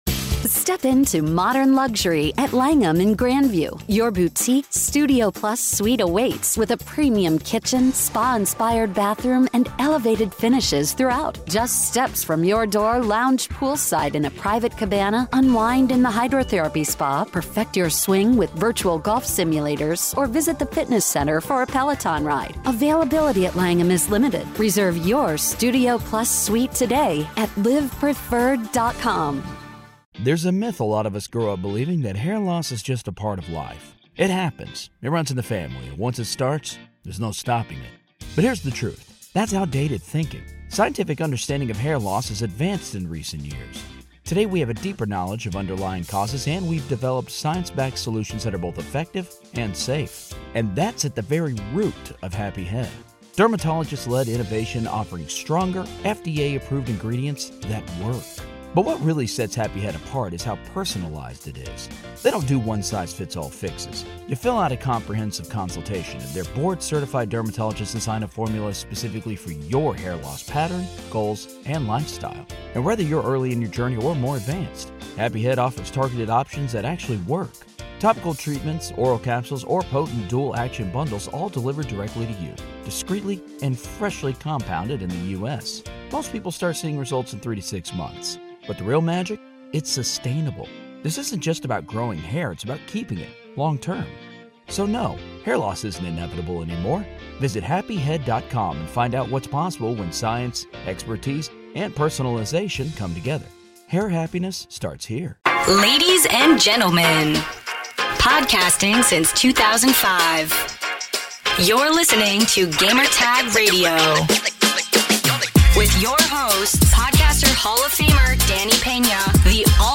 Hitman 3 Interview with IO Interactive